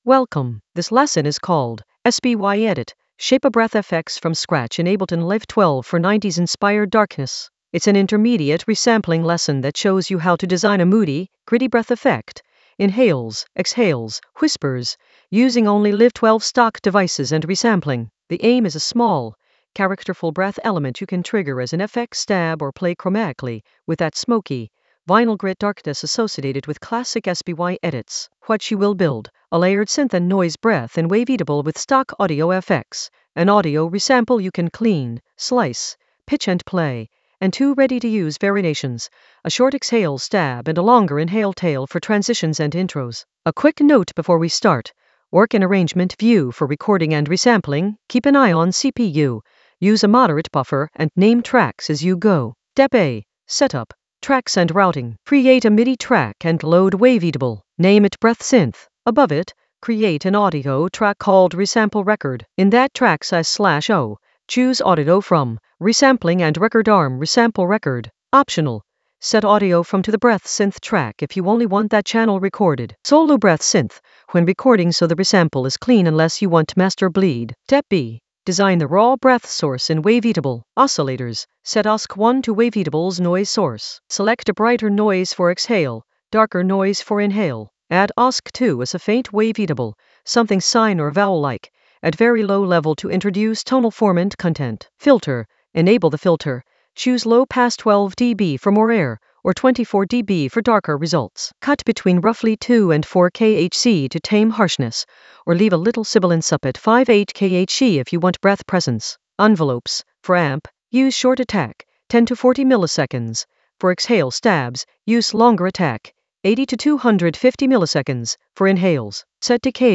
An AI-generated intermediate Ableton lesson focused on S.P.Y edit: shape a breath FX from scratch in Ableton Live 12 for 90s-inspired darkness in the Resampling area of drum and bass production.
Narrated lesson audio
The voice track includes the tutorial plus extra teacher commentary.